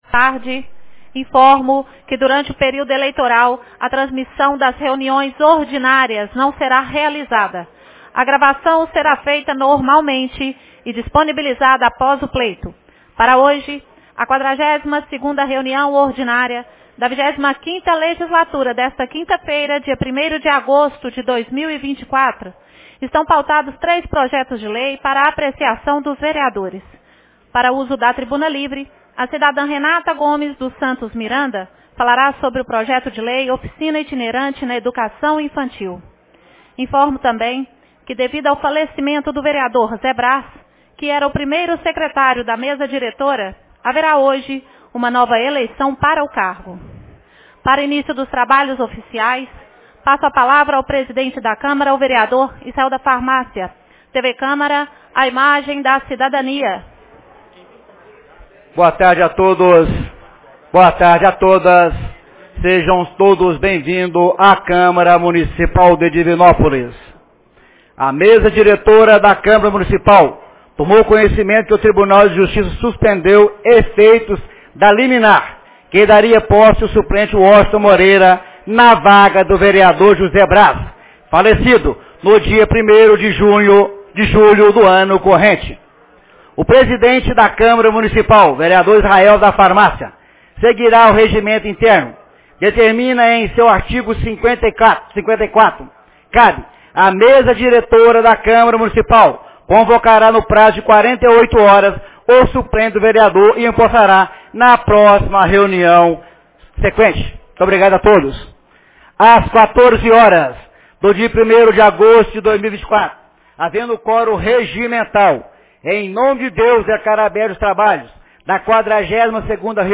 42ª Reunião Ordinária 01 de agosto de 2024